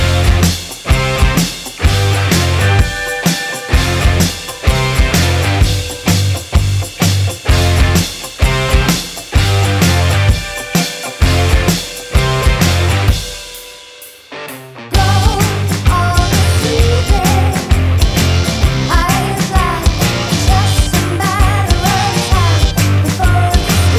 no Backing Vocals Indie / Alternative 3:42 Buy £1.50